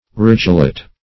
Ridgelet \Ridge"let\, n. A little ridge.